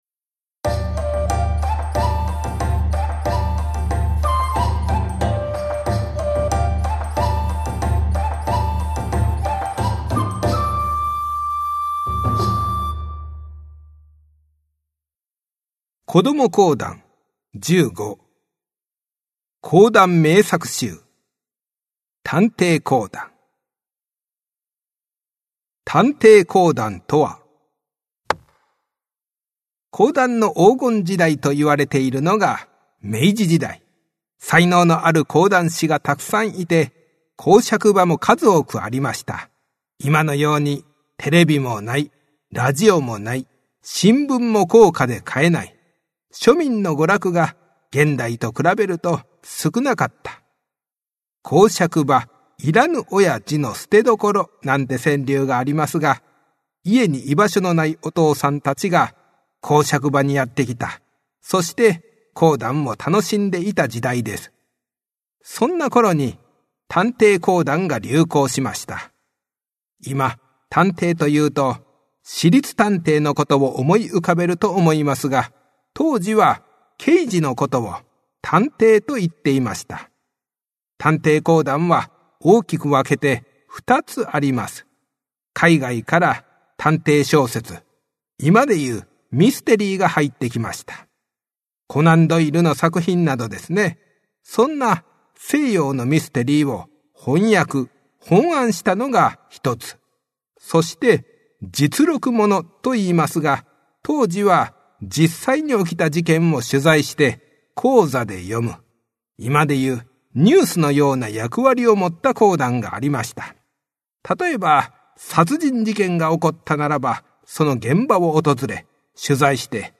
[オーディオブック] こども講談 十五
お子様向け短めの講談を楽しんでください♪
講談師。